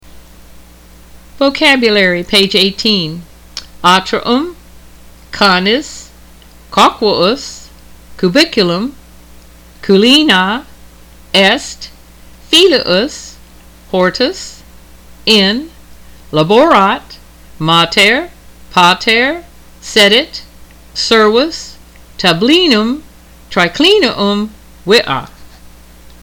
I have made mp3 files of vocabulary for the Latin students.
2011vocabularypage8.mp3